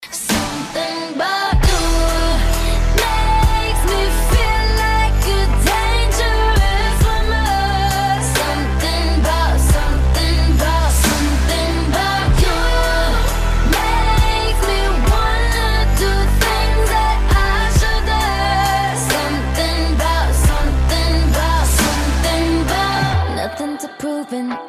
• Качество: 181, Stereo
женский вокал
RnB
Electropop